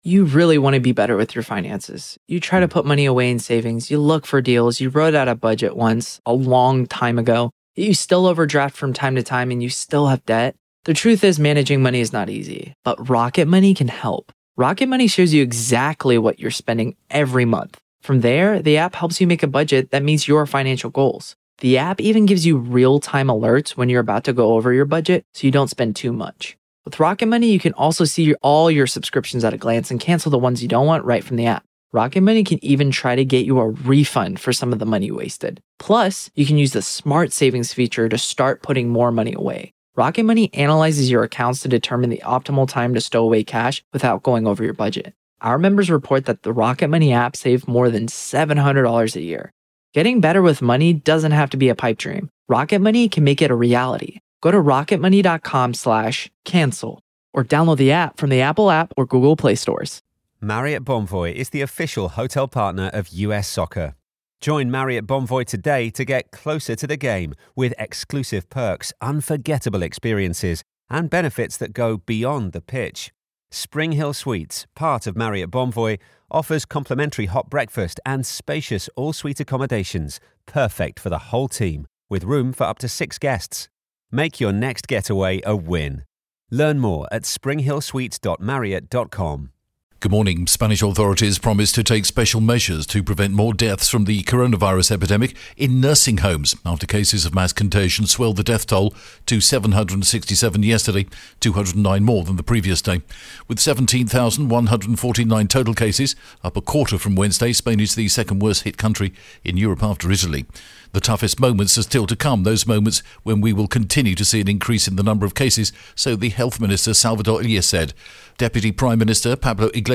The latest Spanish news headlines in English: March 20th am